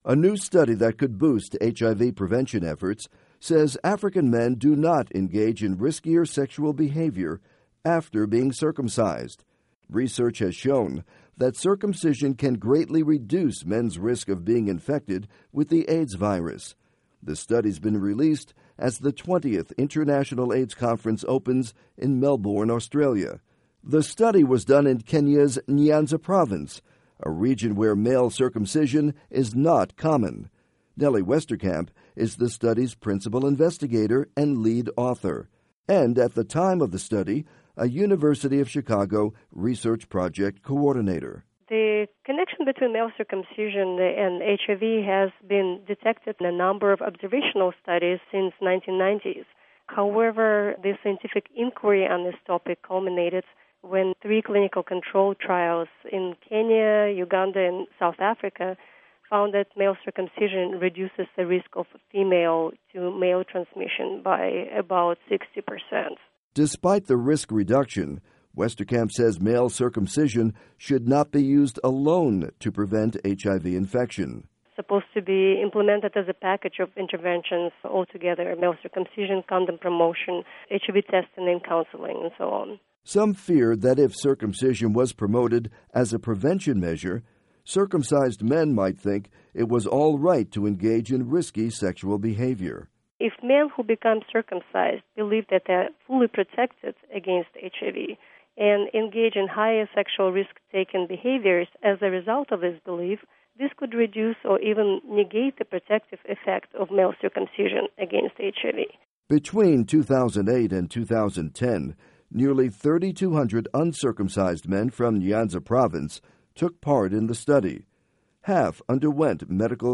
report on HIV and male circumcision